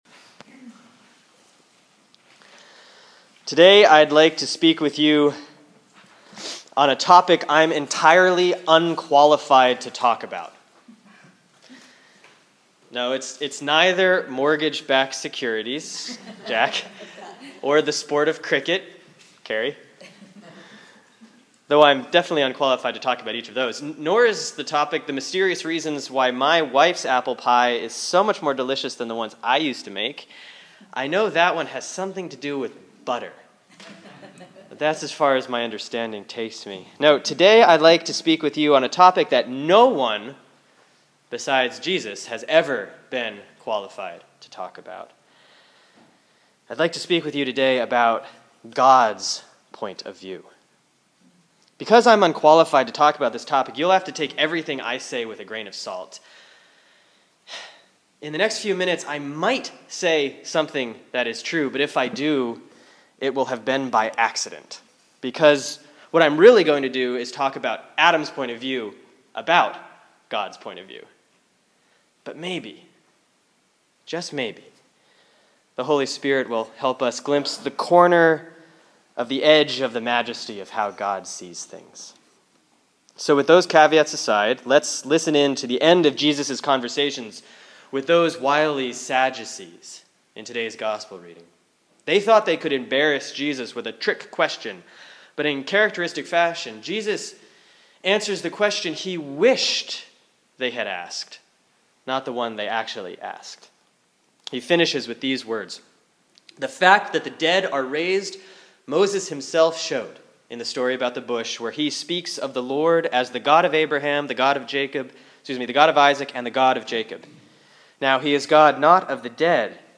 (Sermon for Sunday, November 10, 2013 || Proper 27C || Luke 20:27-38)